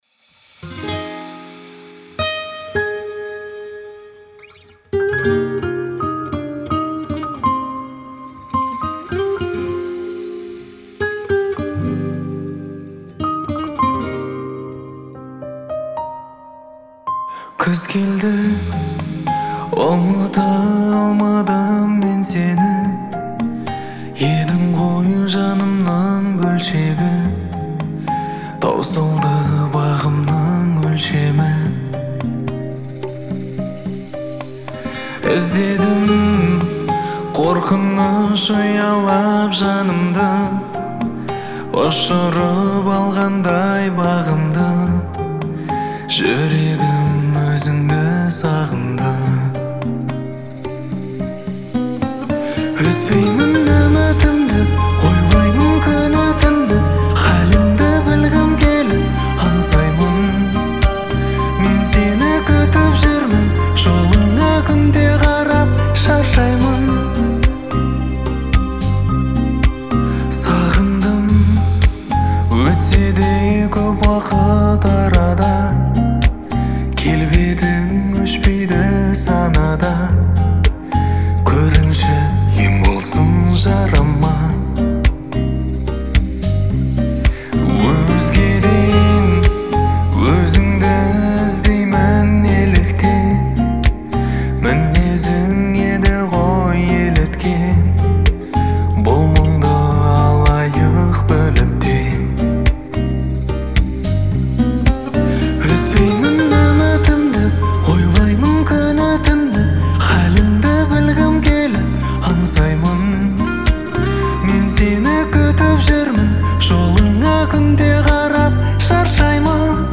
Трек размещён в разделе Поп / Казахская музыка.